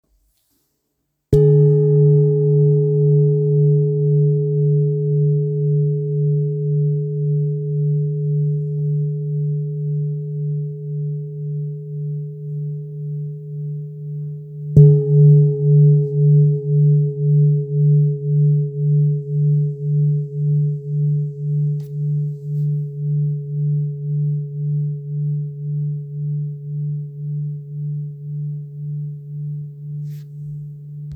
Kopre Singing Bowl, Buddhist Hand Beaten, Antique Finishing, 17 by 17 cm,
Material Seven Bronze Metal
It is accessible both in high tone and low tone .
In any case, it is likewise famous for enduring sounds.